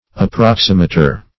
Approximator \Ap*prox"i*ma`tor\, n. One who, or that which, approximates.